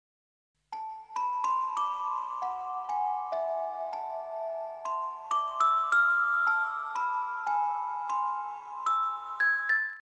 Music Box Version